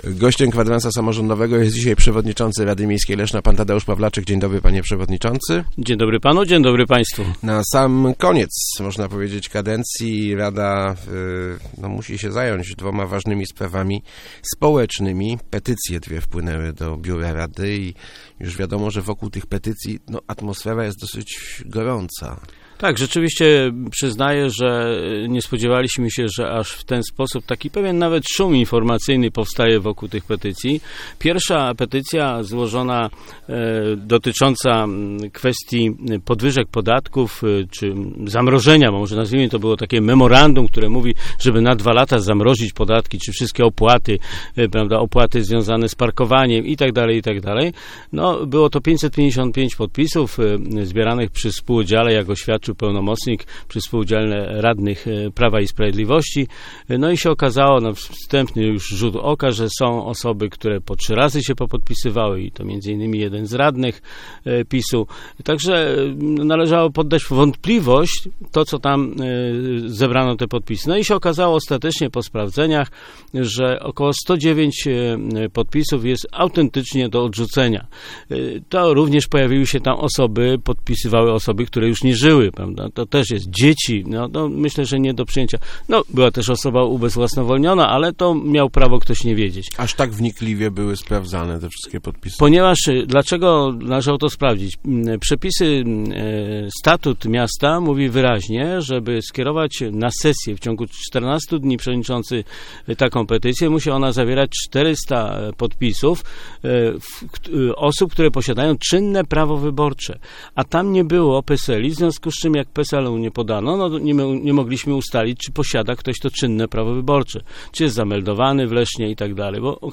Gościem Kwadransa był Tadeusz Pawlaczyk, przewodniczący RML.